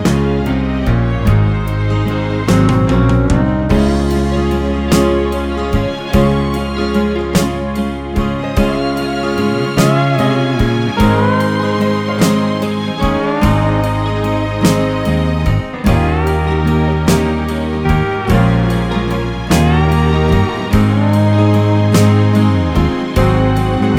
no Backing Vocals Country (Female) 3:16 Buy £1.50